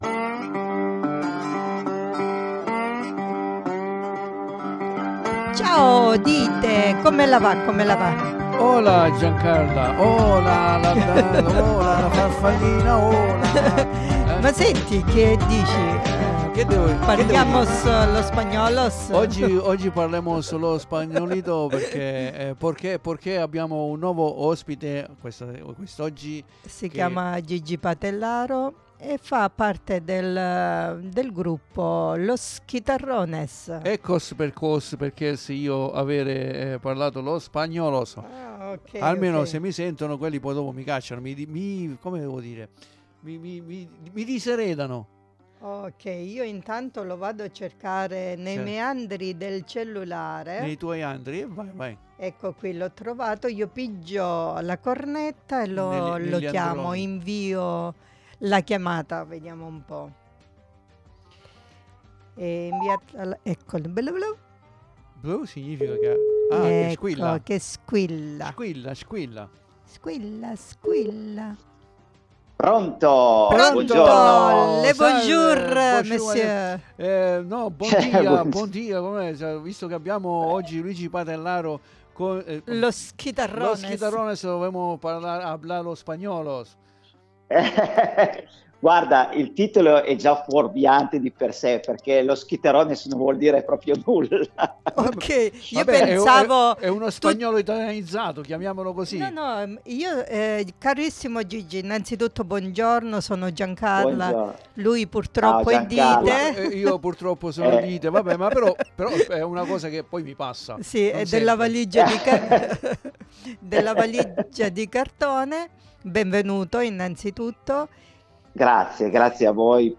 NON VI SPIFFERO ALTRO E VI CONSIGLIO DI ASCOLTARE L'INTERVISTA CONDIVISA QUI IN DESCRIZIONE.